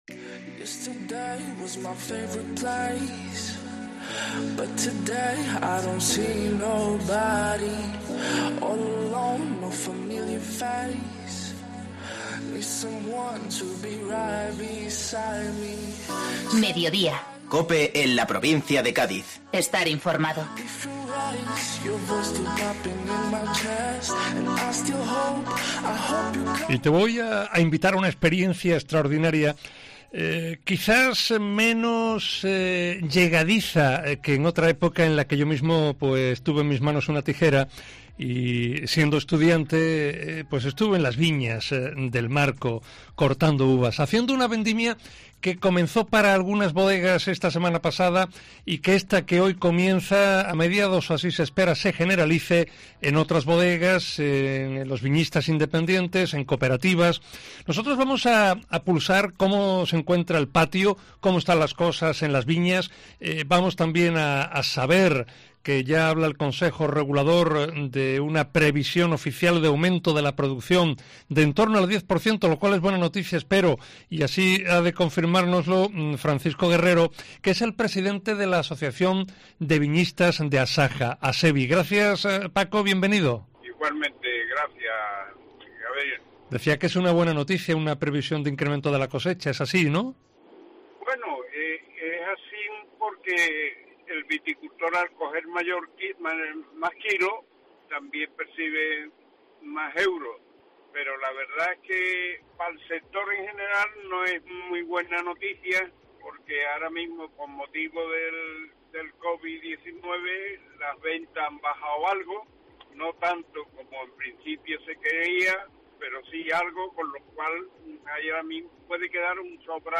Entrevista para 'Mediodía Cope Provincia de Cádiz'